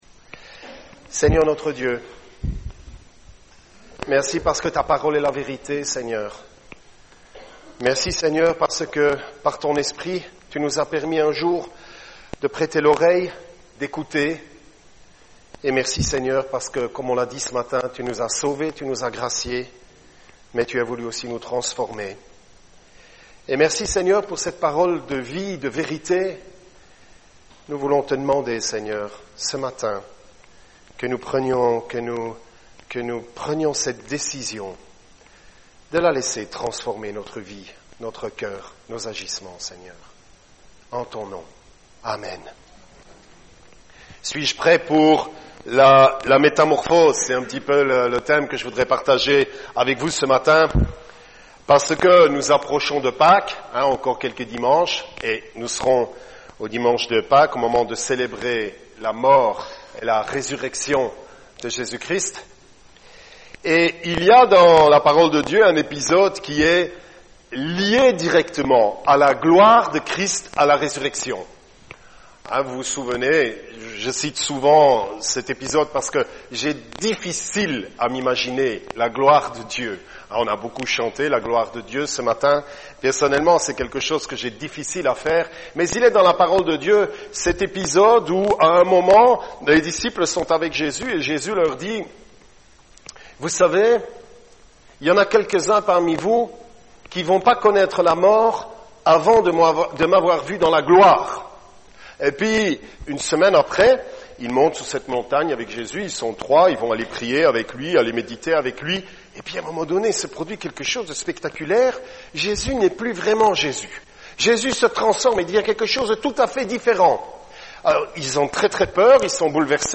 Passage: Jérémie 18 : 1-6 Type De Service: Dimanche matin